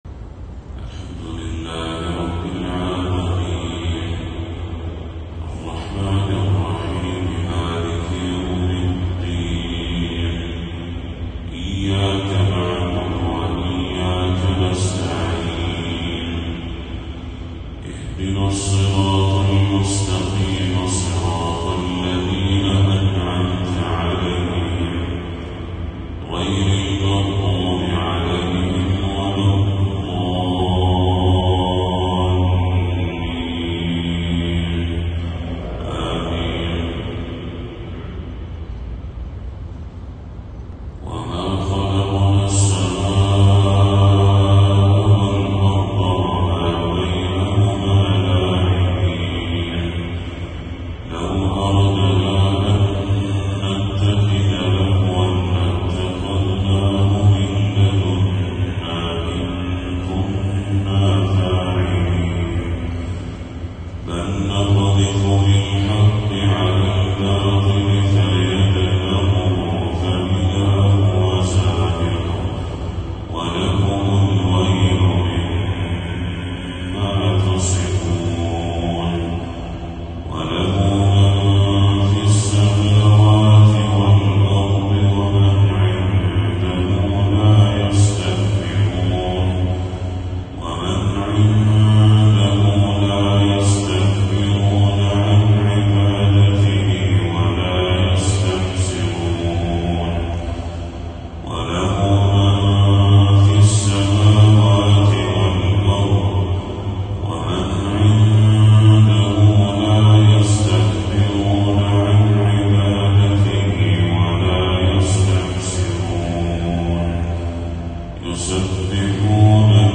تلاوة من سورة الأنبياء للشيخ بدر التركي | فجر 15 صفر 1446هـ > 1446هـ > تلاوات الشيخ بدر التركي > المزيد - تلاوات الحرمين